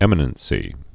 (ĕmə-nən-sē)